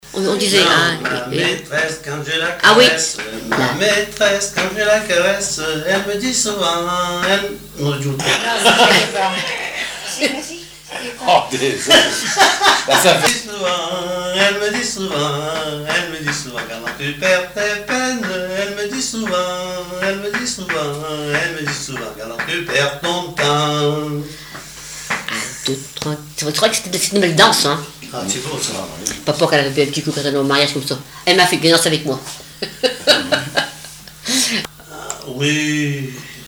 Chants brefs - A danser
danse : scottich sept pas
Pièce musicale inédite